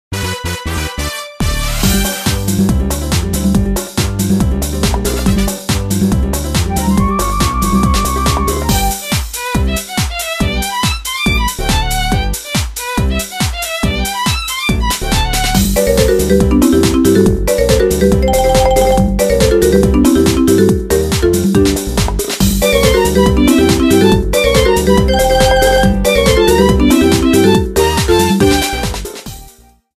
Trimmed to 30 seconds and applied fadeout
Fair use music sample